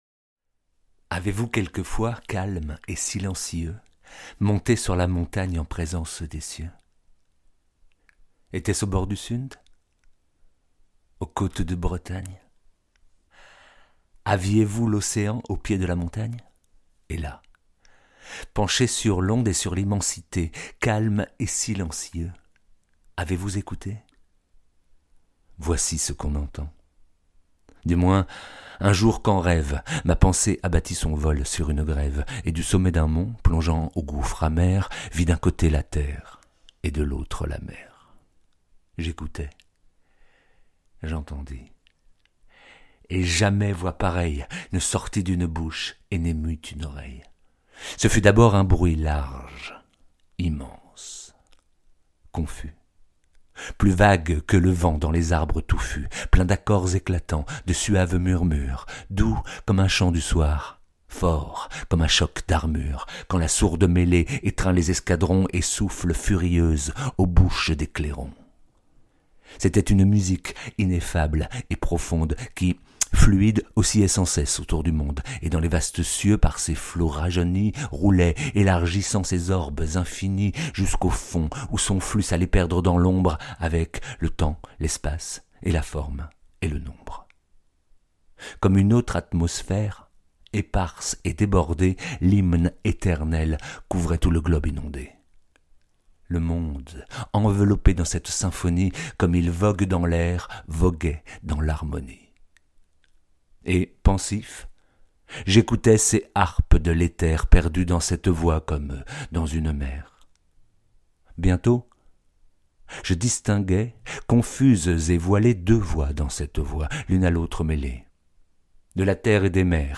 Bandes-son
31 - 62 ans - Baryton